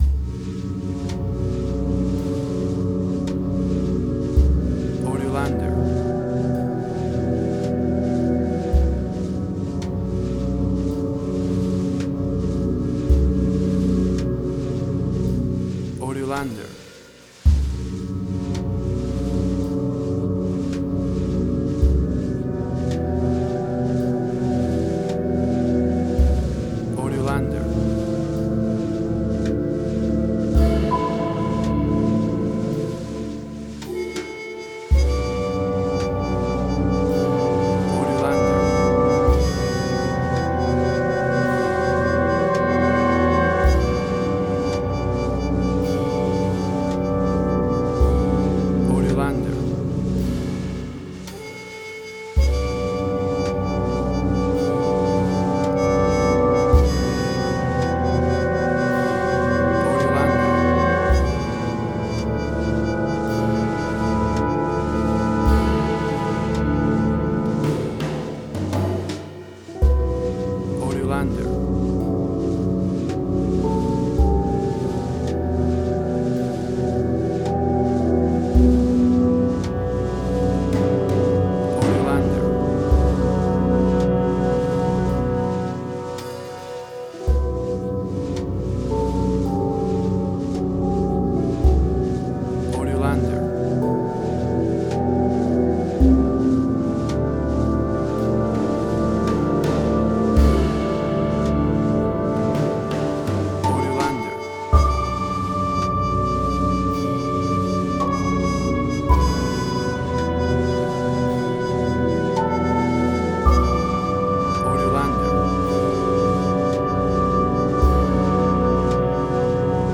Modern Film Noir.
Tempo (BPM): 55